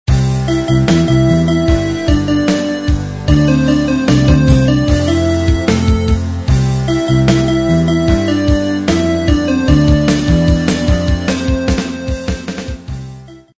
• Пример мелодии содержит искажения (писк).